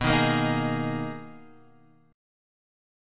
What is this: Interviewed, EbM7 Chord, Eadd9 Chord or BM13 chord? BM13 chord